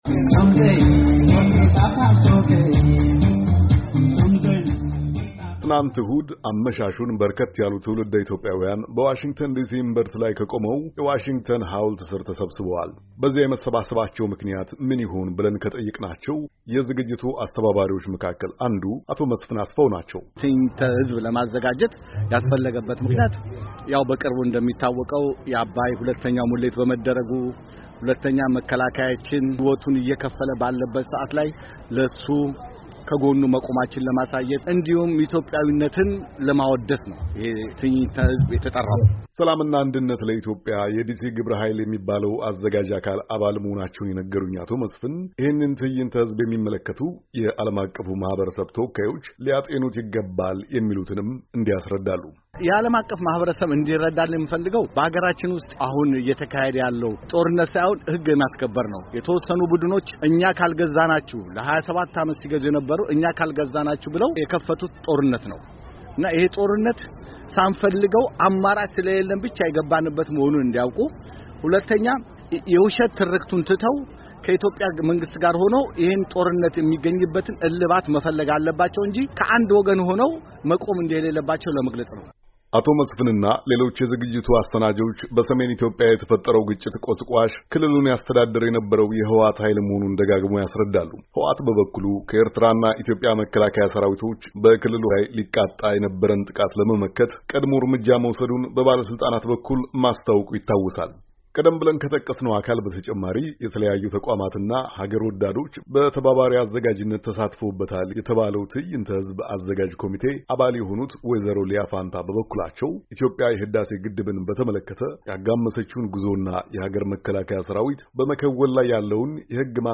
ከትዐዕይንተ -ህዝቡ አዘጋጆች ጋር አጭር ቆይታ አድርጓል።